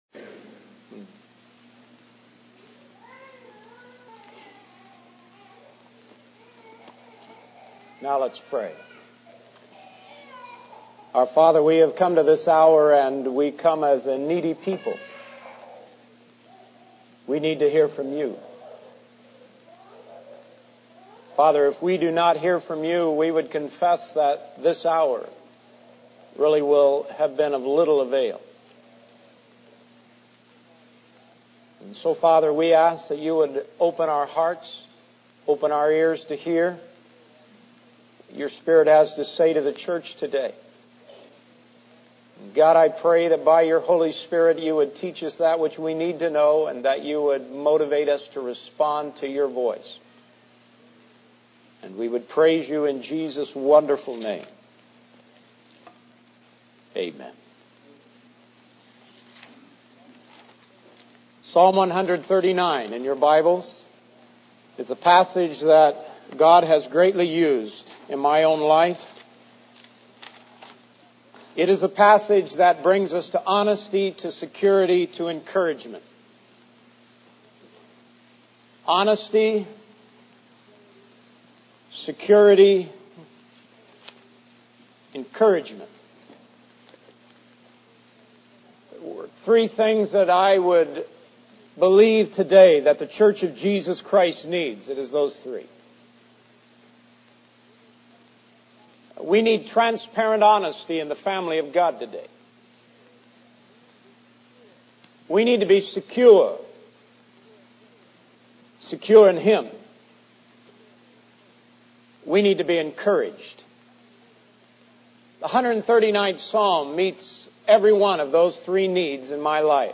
In this sermon, the speaker emphasizes the need for transparent honesty, security, and encouragement in the family of God. He shares how the 139th Psalm has personally encouraged him in specific ways over the past two years.